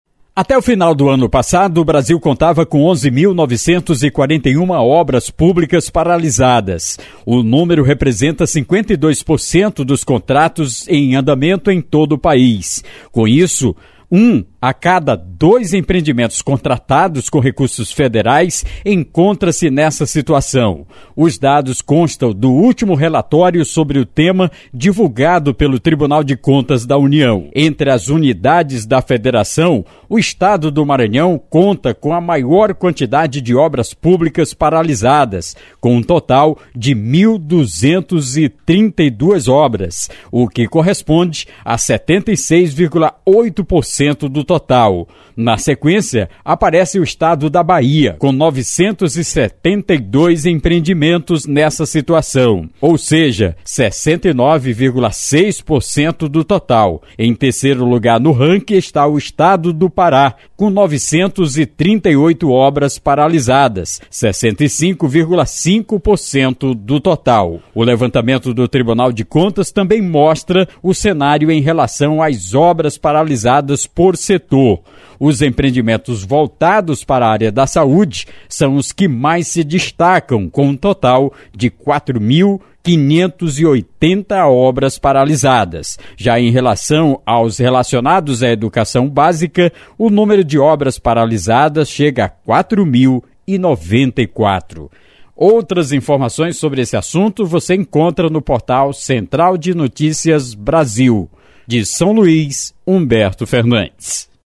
Matérias em áudio